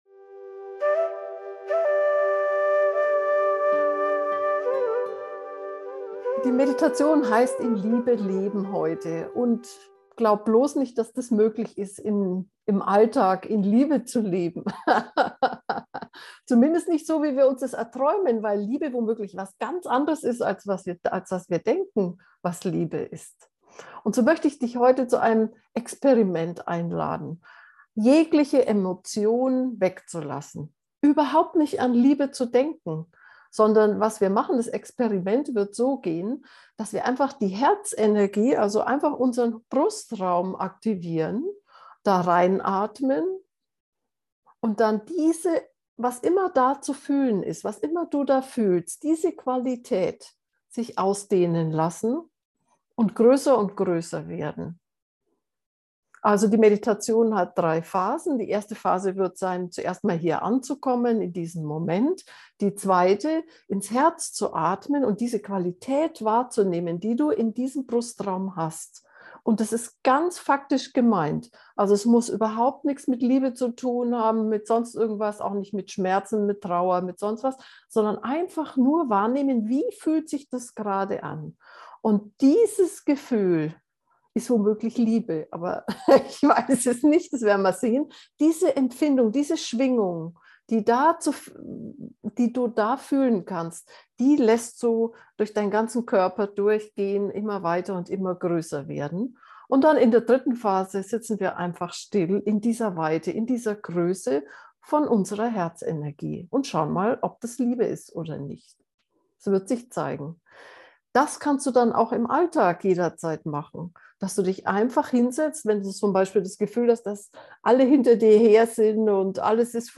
in-liebe-leben-gefuehrte-meditation